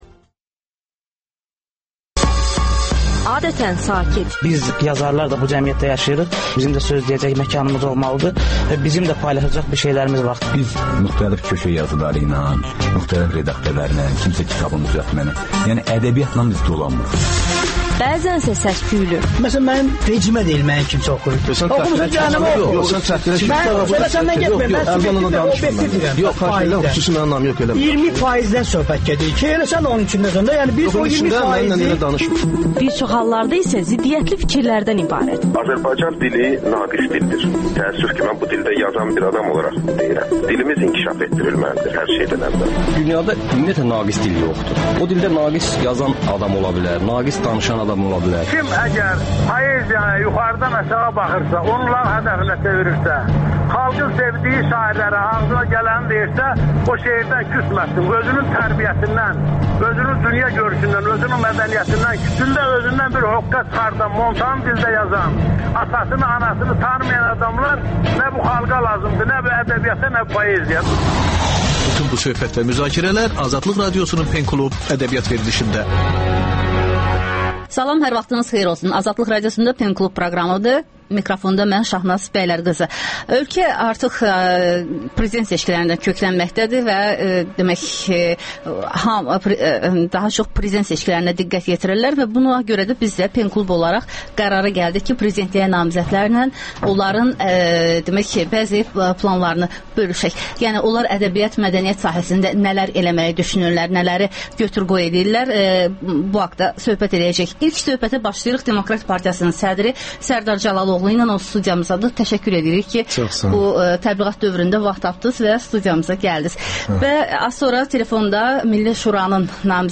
Prezidentliyə namizədlər Sərdar Cəlaloğlu, Cəmil Həsənli və Hafiz Hacıyevin radiodebatı.